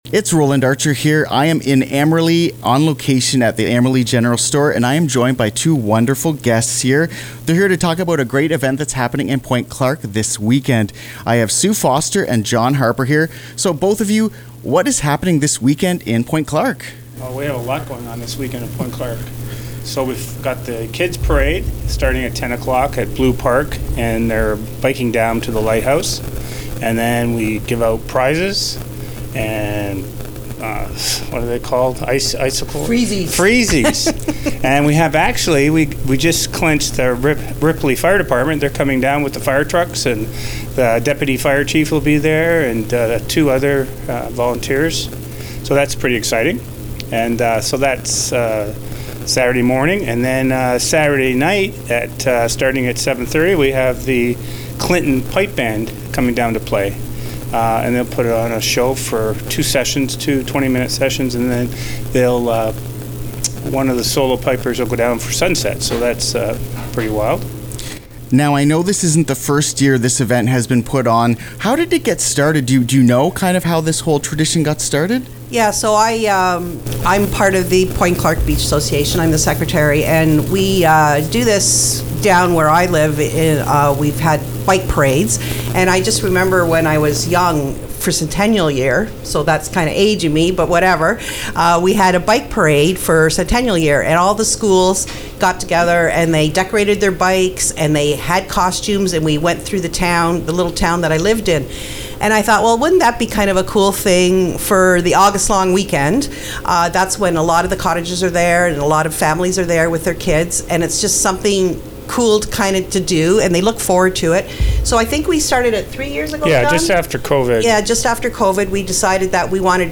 on-location in Amberley this morning